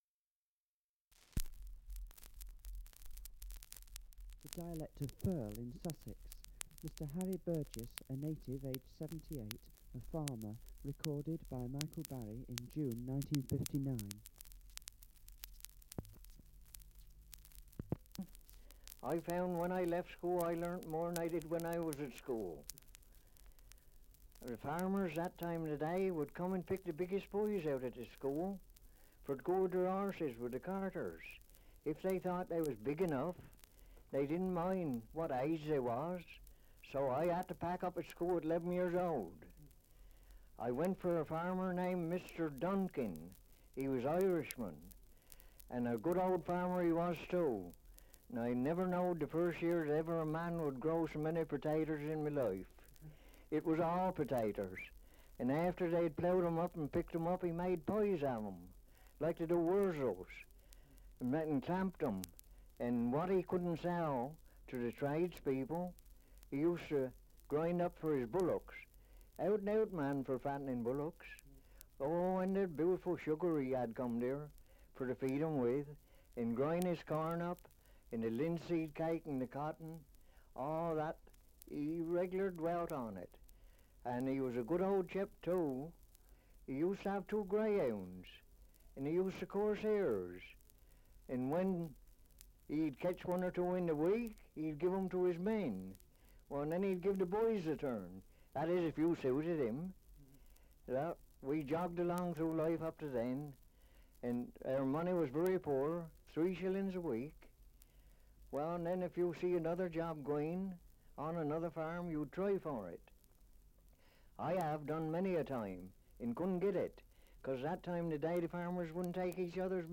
1 - Survey of English Dialects recording in Firle, Sussex
78 r.p.m., cellulose nitrate on aluminium